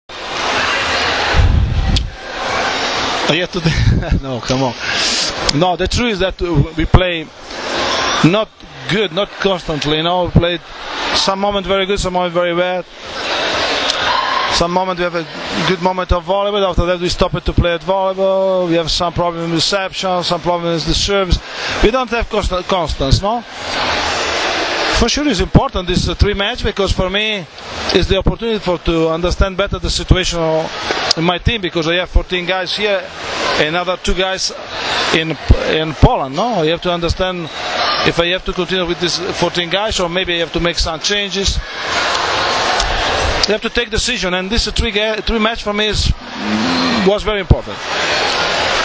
IZJAVA ANDREE ANASTAZIJA